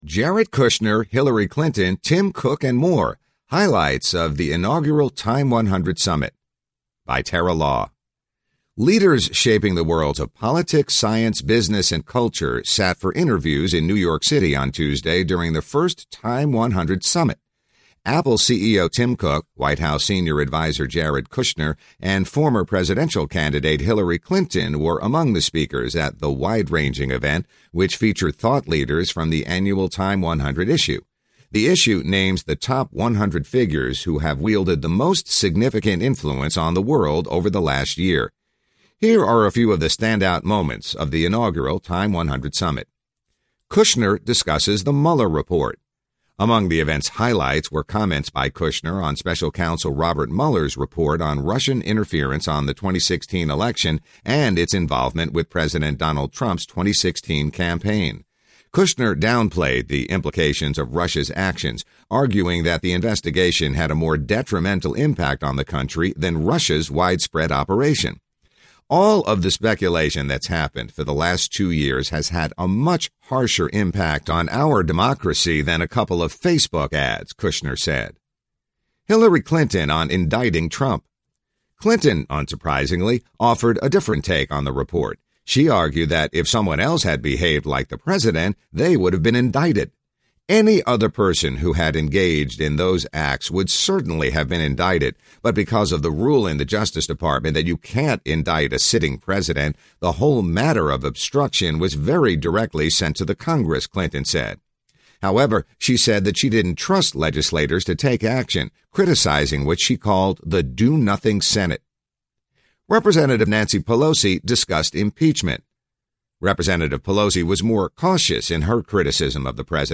Leaders shaping the worlds of politics, science, business and culture sat for interviews in New York City on Tuesday during the first TIME 100 Summit. Apple CEO Tim Cook, White House Senior Advisor Jared Kushner and former presidential candidate Hillary Clinton were among the speakers at the wide-ranging event, which featured thought leaders from the annual TIME 100 issue.